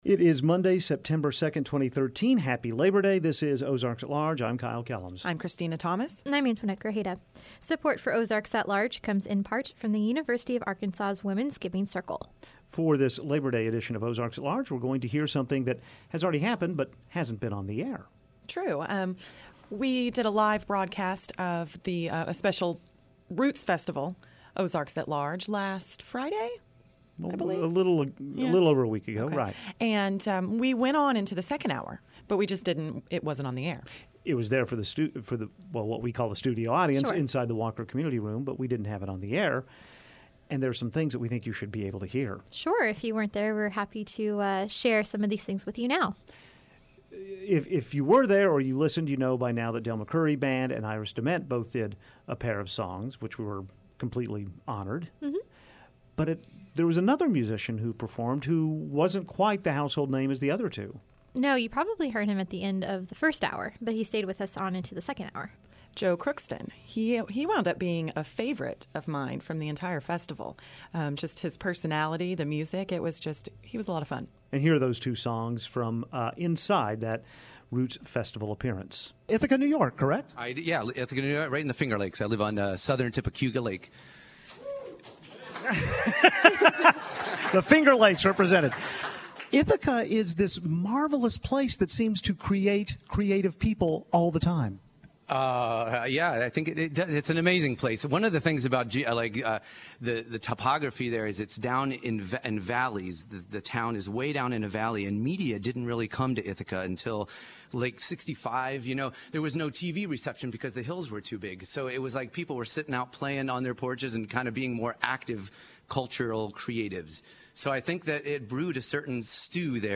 And, we have a special "Labor Insecurity Day" montage, containing film and musical references to people who will likely soon be looking for work.